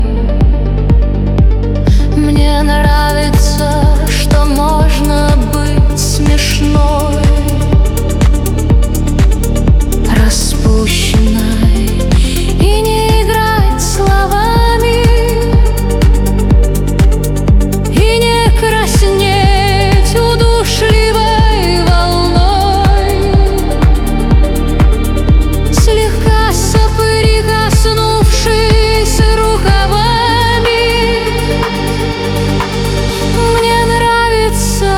Жанр: Электроника / Русские
Electronic